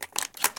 shotgunReload3.ogg